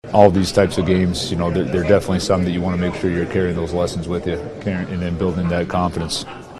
Muse says an impressive win against the powerful Oilers is something The Penguins can build on.